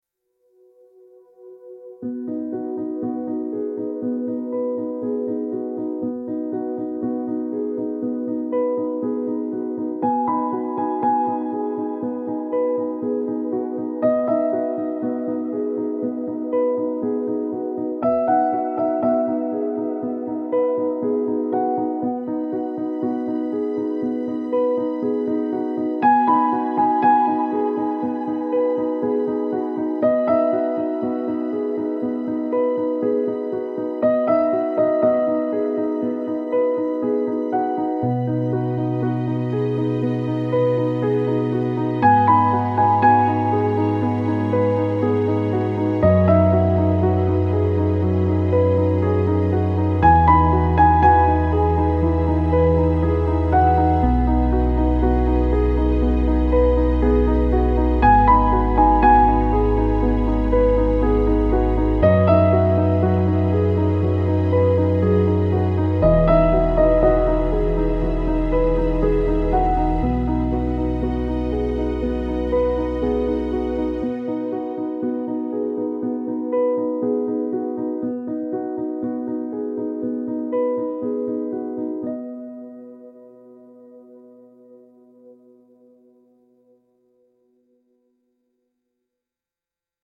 minimal piano and ambient pads creating an authentic candid atmosphere